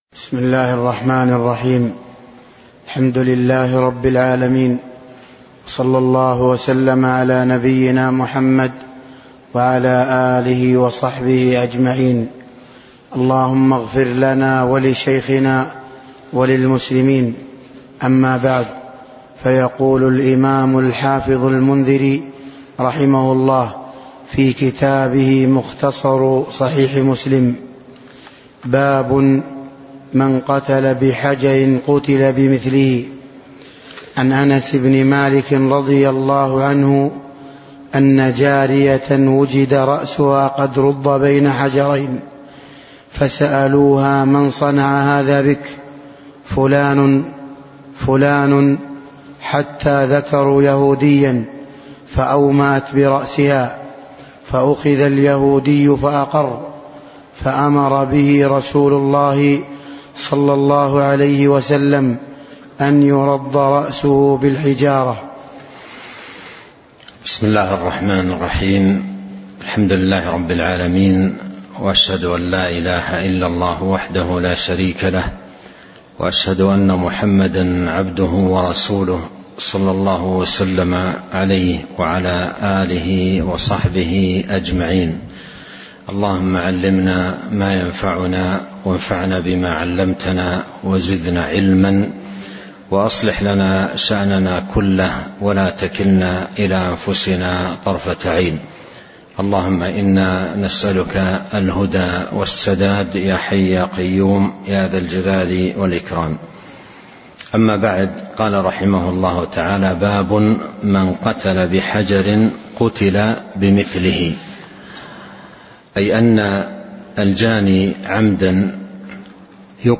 تاريخ النشر ٤ ربيع الأول ١٤٤٣ هـ المكان: المسجد النبوي الشيخ